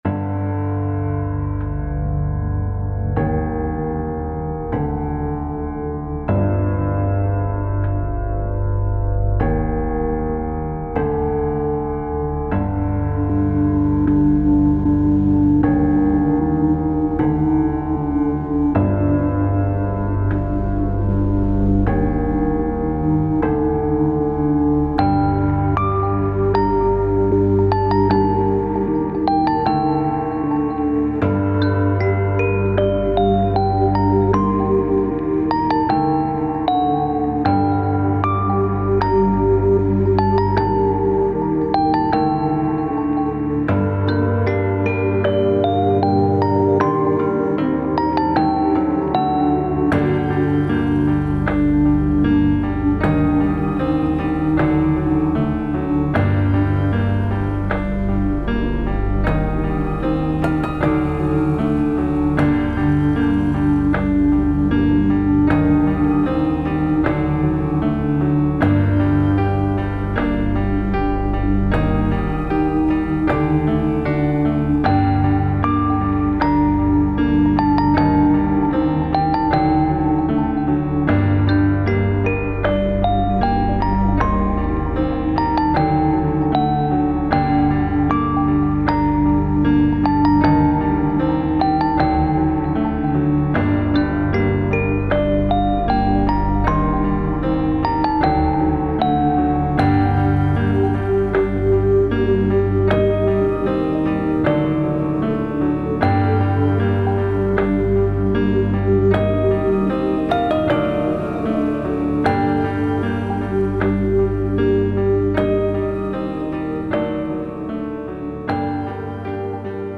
タグ: 寂しい/悲しい 暗い 虚無/退廃 コメント: 滅亡した国の城跡をイメージした楽曲。